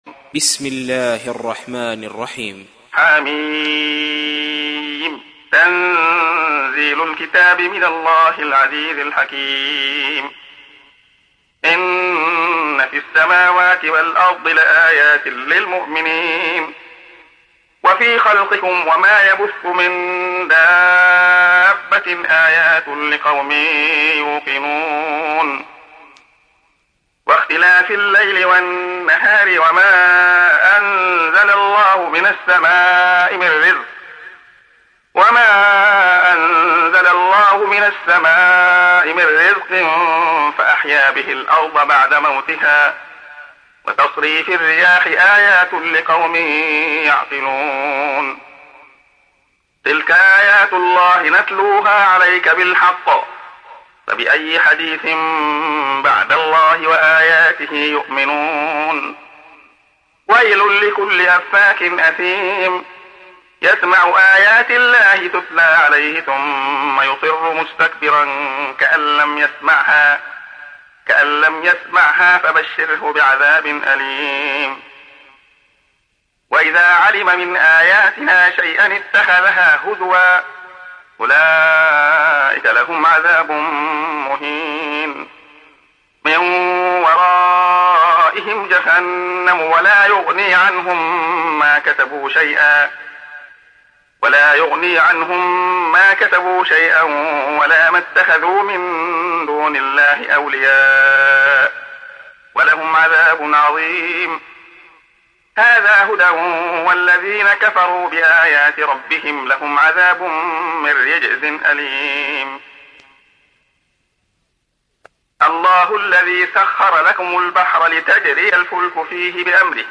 تحميل : 45. سورة الجاثية / القارئ عبد الله خياط / القرآن الكريم / موقع يا حسين